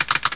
KEYCLICK.WAV